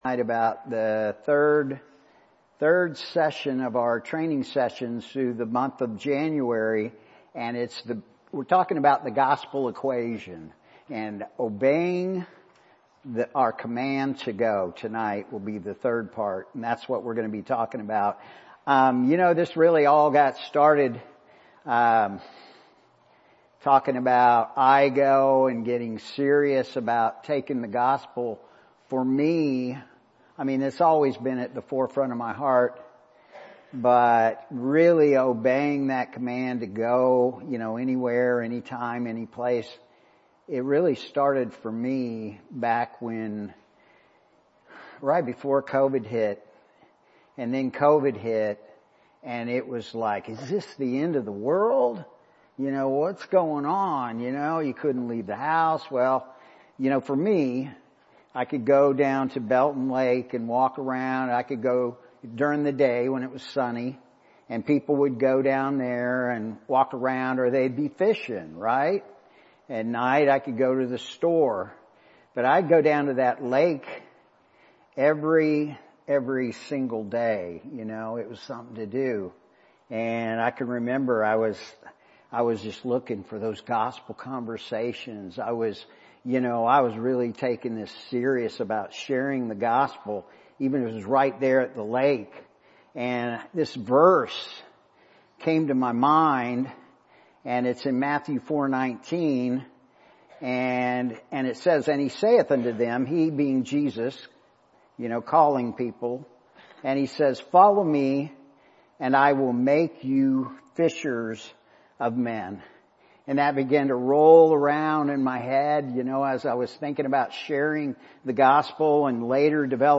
Sermons | Heartland Baptist Fellowship
guest speaker